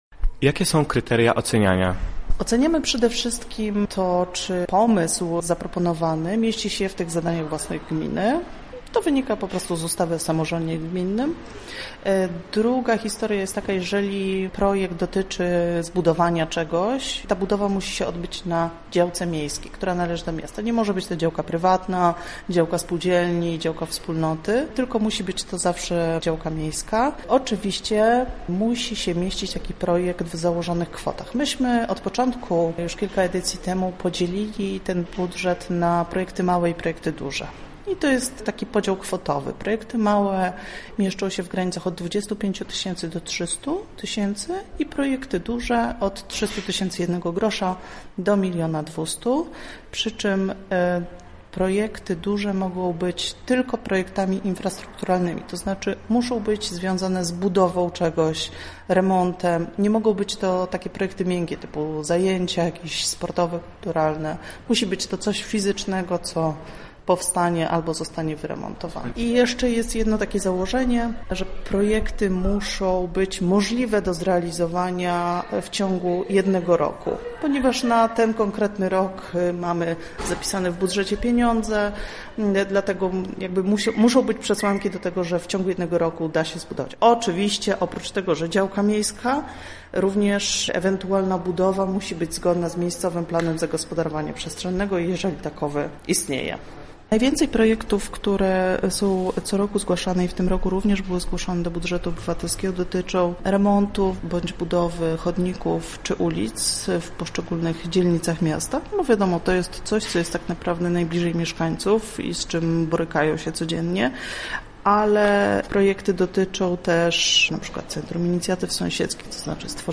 w rozmowie z naszym reporterem